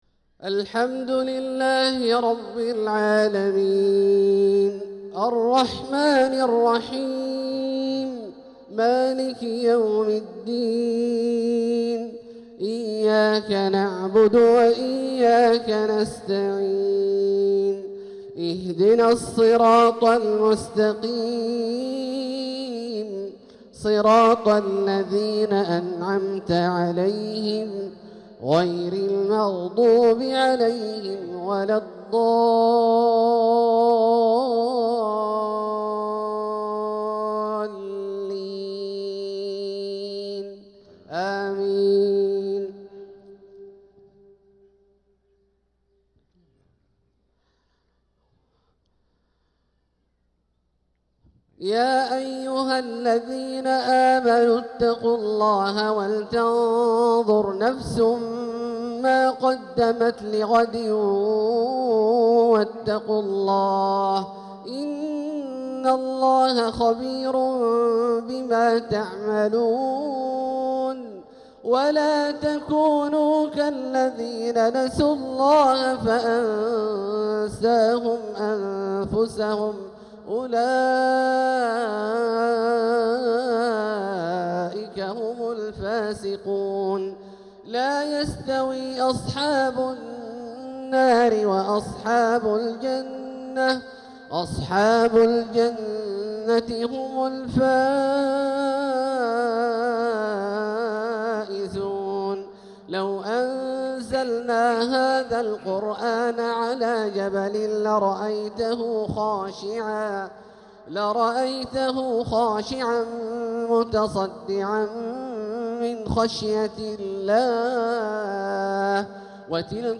صلاة الجمعة 7-9-1446هـ خواتيم سورة الحشر 18-24 | Jumu'ah prayer from Surat al-Hashr 7-3-2025 > 1446 🕋 > الفروض - تلاوات الحرمين